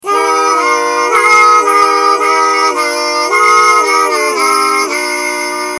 Oh, and I was wondering…is it possible to sue mice for copyright infringement? 🙂
la-fast.mp3